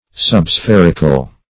Meaning of subspherical. subspherical synonyms, pronunciation, spelling and more from Free Dictionary.
Search Result for " subspherical" : The Collaborative International Dictionary of English v.0.48: Subspherical \Sub*spher"ic*al\, a. Nearly spherical; having a figure resembling that of a sphere.